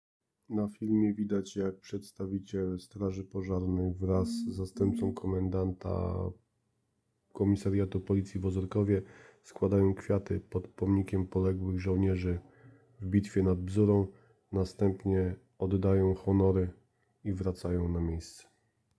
Obchody 86 rocznicy "Bitwy nad Bzurą"